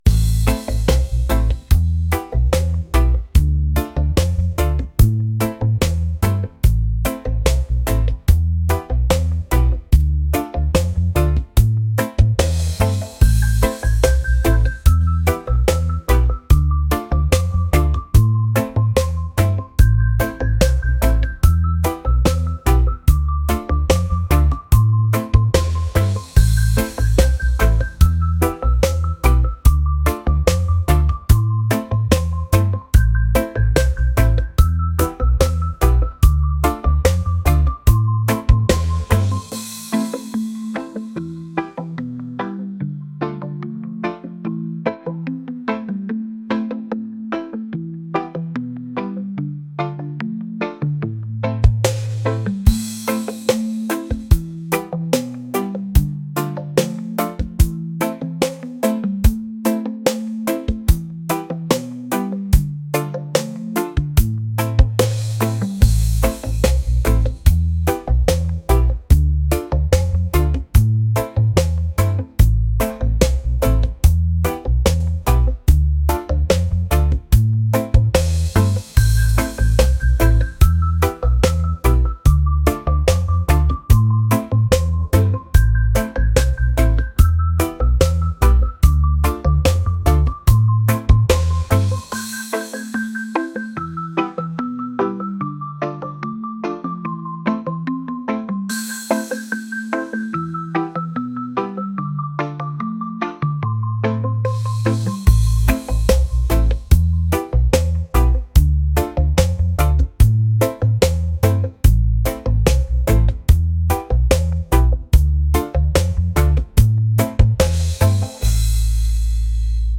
reggae | lounge | soul & rnb